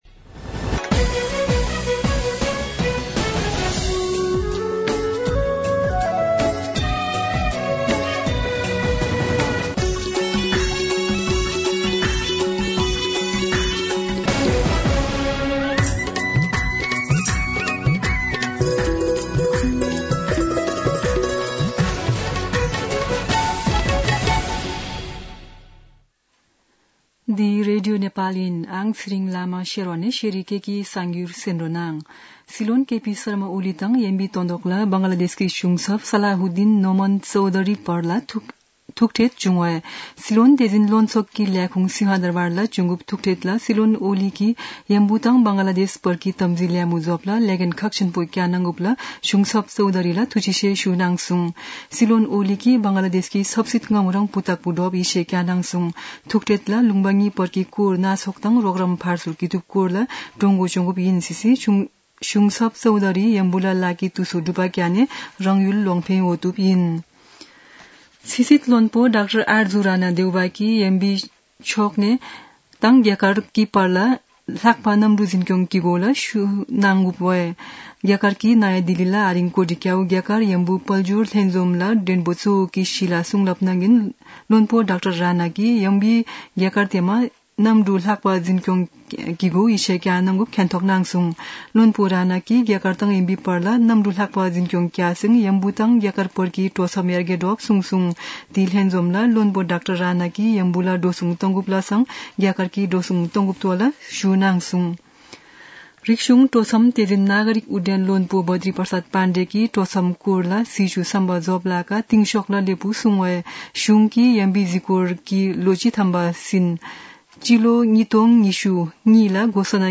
शेर्पा भाषाको समाचार : ६ पुष , २०८१
Sherpa-news-2.mp3